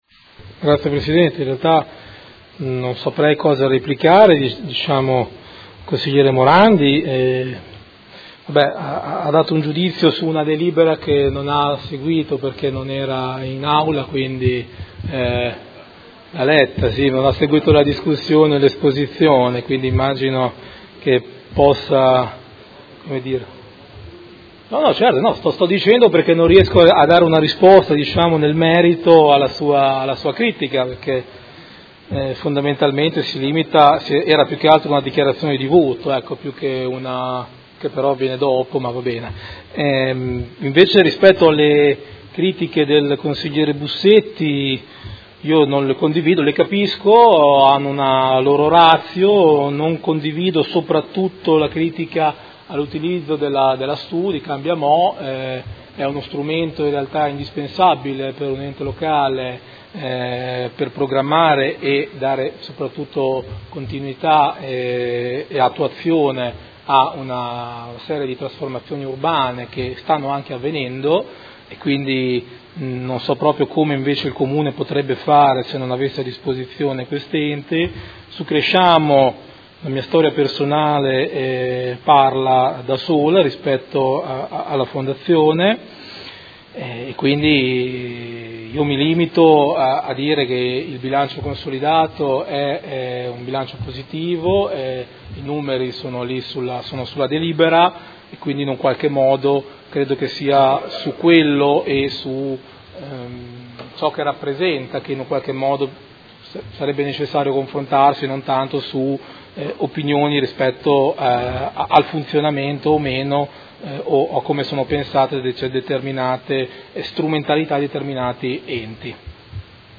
Seduta del 27/09/2018. Replica a dibattito su proposta di deliberazione: Bilancio Consolidato 2017 del Gruppo Comune di Modena - Verifica finale del controllo sulle società partecipate per l’esercizio 2017 e monitoraggio infrannuale 2018